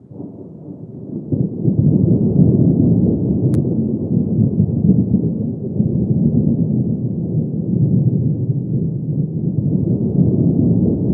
THUNDER 3 -L.wav